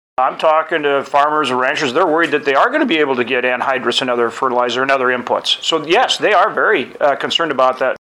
North Dakota Senator John Hoeven also weighed in.